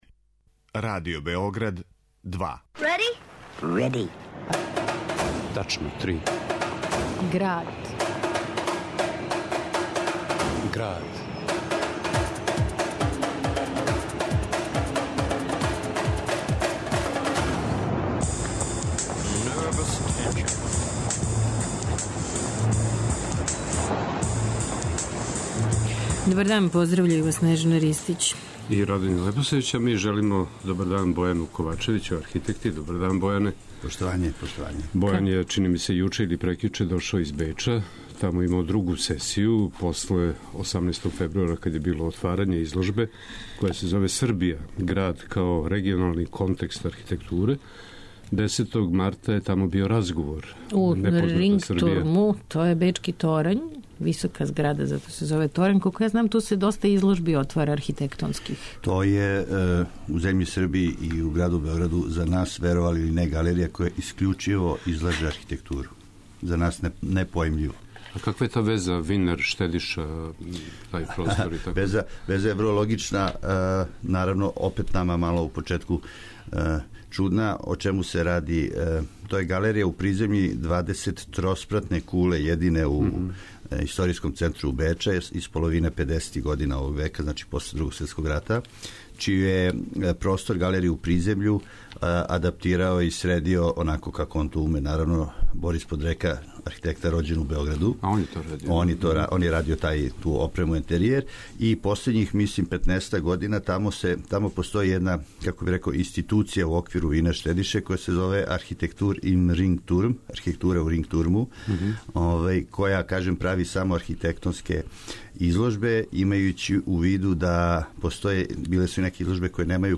уз документарне снимке